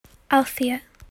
I’m english, and I would say: